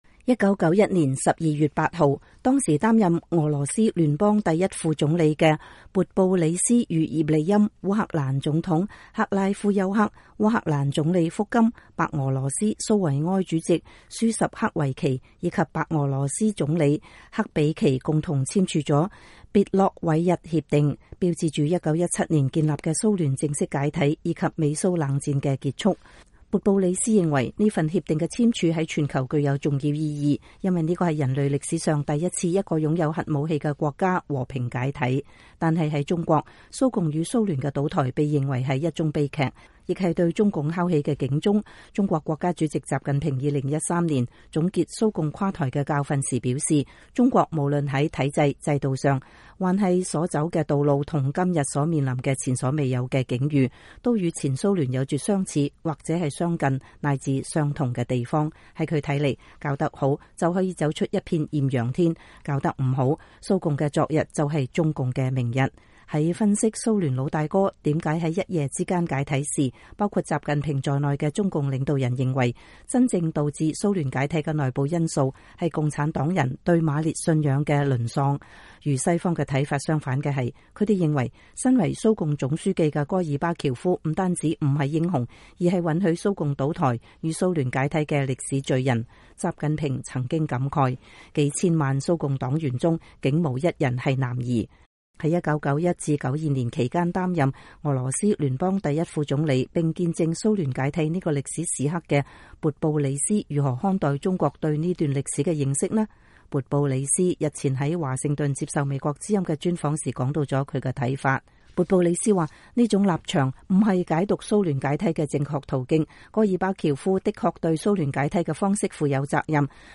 勃布里斯日前在華盛頓接受美國之音的專訪時談到了他的看法。